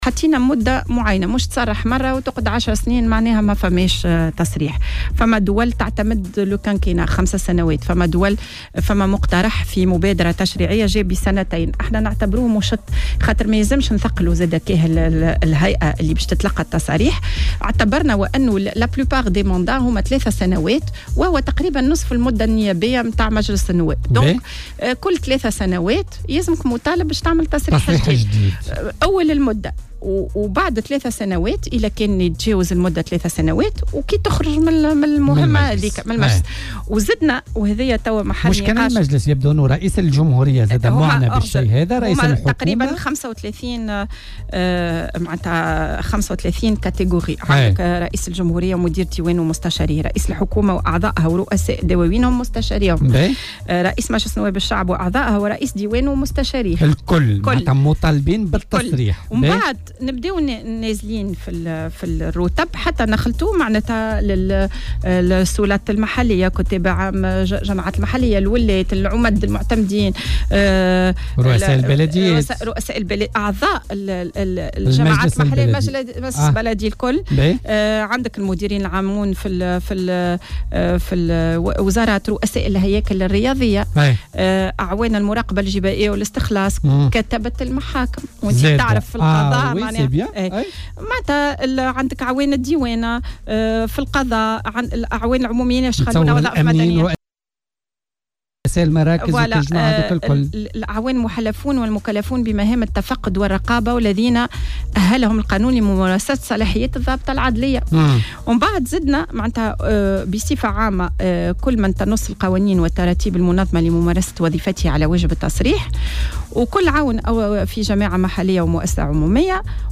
وأكدت ضيفة "بوليتيكا" على "الجوهرة اف أم" أن مشروع القانون أخذ بعين الاعتبار أيضا تضارب المصالح بين القطاعين العام والخاص ليتم اضافة أطراف من القطاع الخاص.